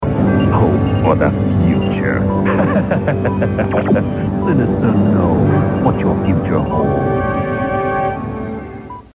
Soon after THE FINAL DECISION aired, the voice of Mr. Sinister was changed and, consequently, when it came time to rerun THE FINAL DECISION, the producers decided to change the voice-over to match the finalized voice.